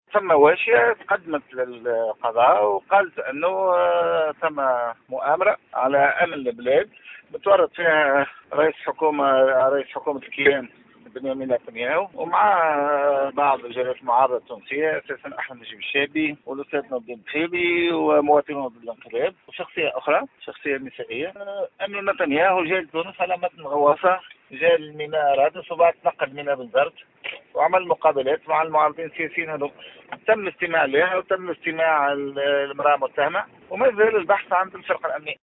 وأضاف ديلو في تصريح للجوهرة أف أم، أنّه تمّ الاستماع إلى المواطنة والشخصية النسائية المُتهمة، والأبحاث متواصلة على مستوى الفرقة الأمنية المتعهدة بالبحث، حسب تعبيره.